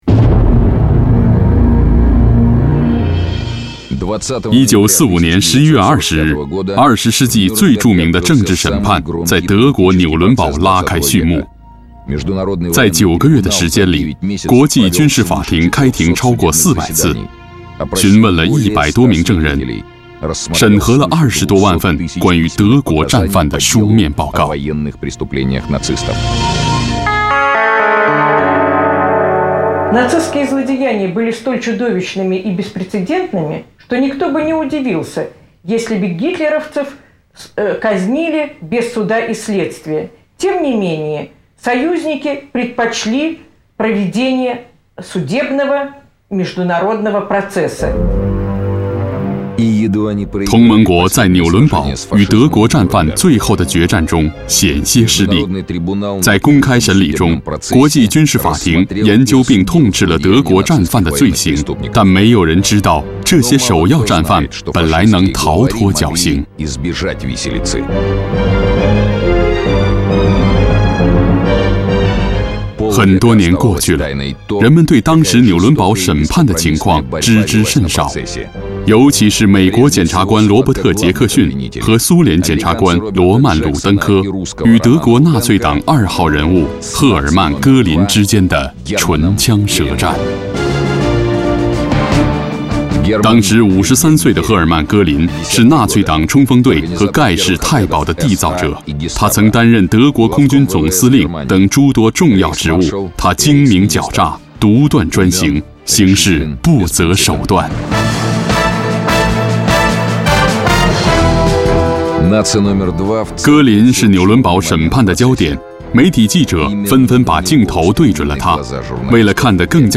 国语中年大气浑厚磁性 、沉稳 、娓娓道来 、男纪录片 、150元/分钟男11 国语 男声 纪录片 cctv高清纪录片 长城内外 历史 恢宏 大气浑厚磁性|沉稳|娓娓道来